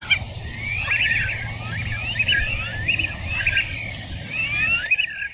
Anas sibilatrix - Pato overo